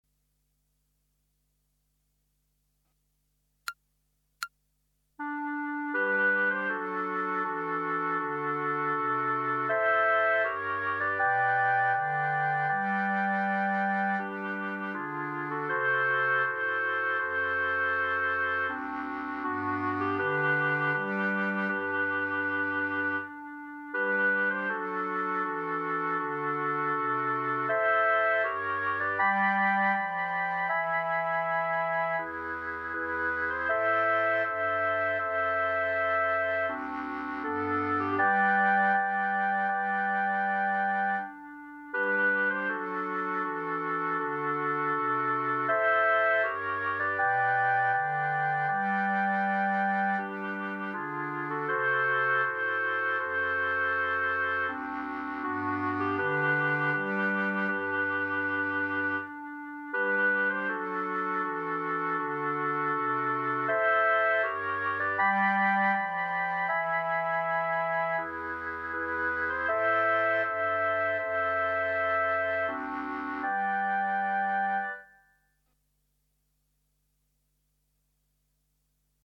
Clarinet Ensemble